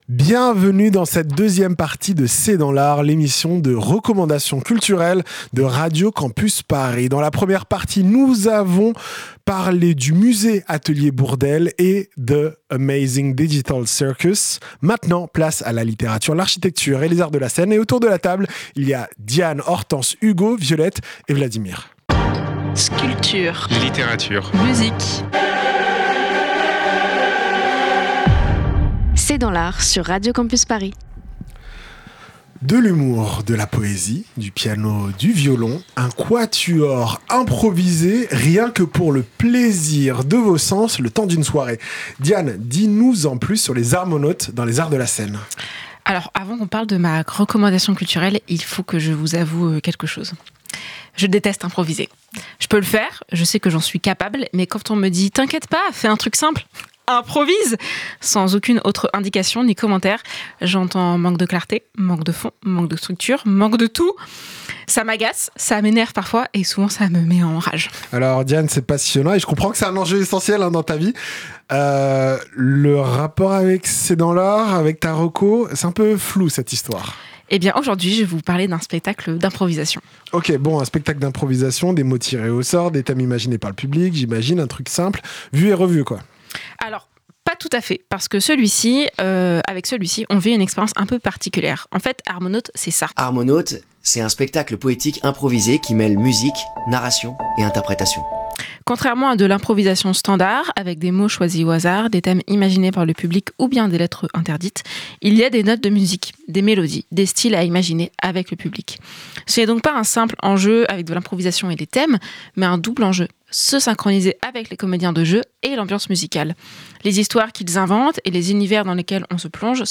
C’est dans l’art, l’émission de recommandation culturelle de Radio Campus Paris.
Type Magazine Culture